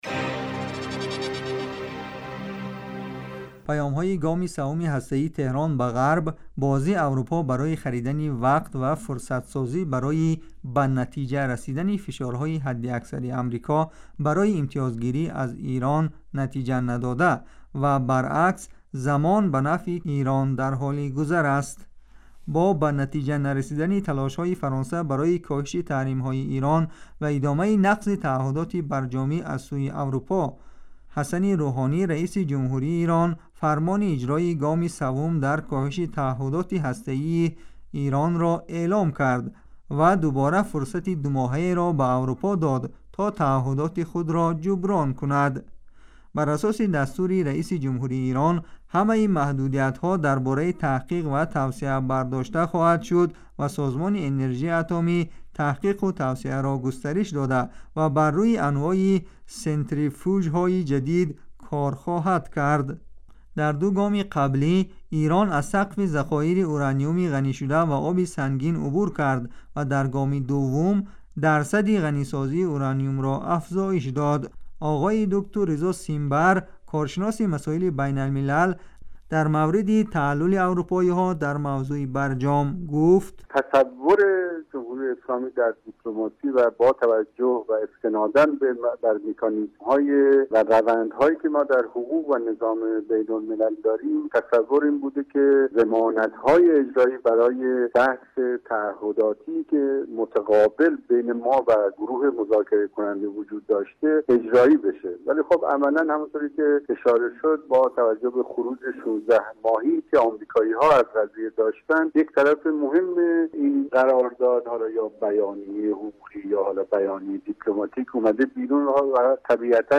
Гузориши вижа.